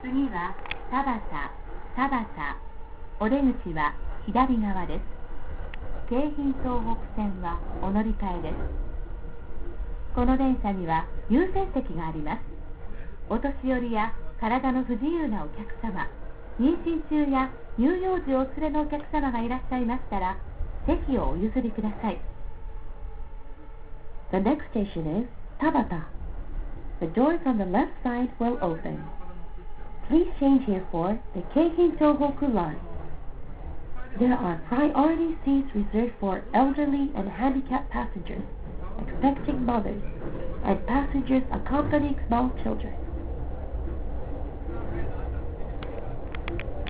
Departure Procedure and Sounds
Yamanote announcement (
Tabata.WAV